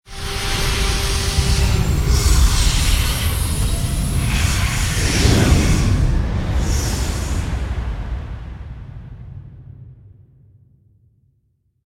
launch10.ogg